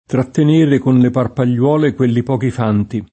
parpagliola [parpal’l’0la] (oggi lett. parpagliuola [parpal’l’U0la]; region. parpaiola [parpaL0la]) s. f. (numism.) — es.: trattenere con le parpagliuole quelli pochi fanti [